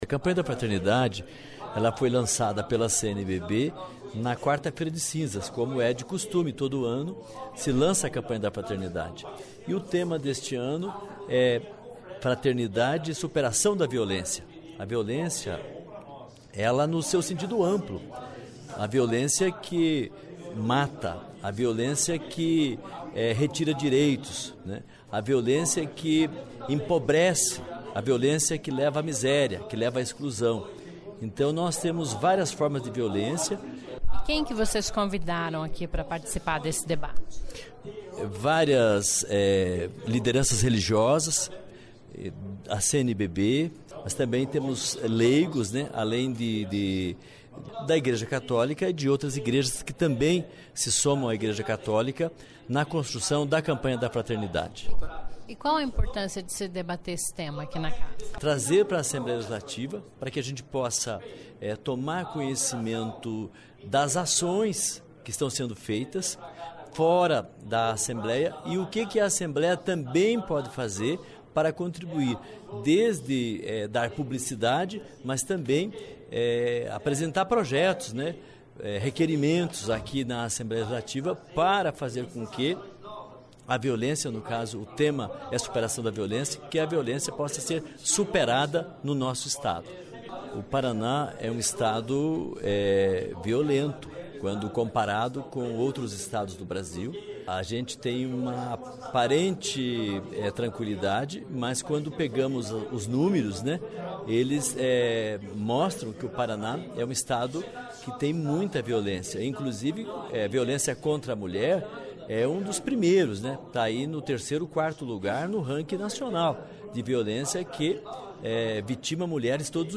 Campanha da Fraternidade que debate superação da violência é tema de encontro na Alep promovido em parceria pelos deputados Péricles de Mello (PT) e Profesor lemos (PT). Acompanhe a entrevista do deputado Professor Lemos sobre a importÑaica de se disctutir o tema no Plenarinho da Assembleia Legislativa.